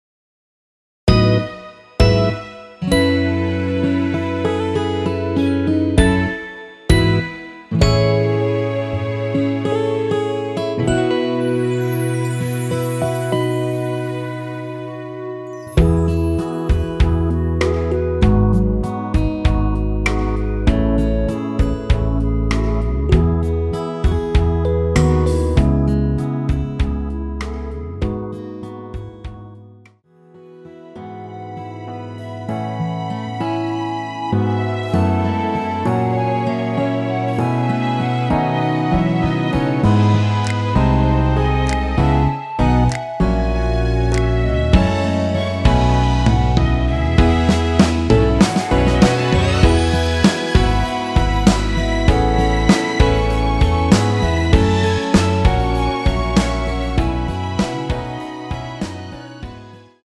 원키에서(+2)올린 멜로디 포함된 MR입니다.(미리듣기 확인)
앞부분30초, 뒷부분30초씩 편집해서 올려 드리고 있습니다.
중간에 음이 끈어지고 다시 나오는 이유는